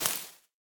Minecraft Version Minecraft Version snapshot Latest Release | Latest Snapshot snapshot / assets / minecraft / sounds / block / cobweb / break2.ogg Compare With Compare With Latest Release | Latest Snapshot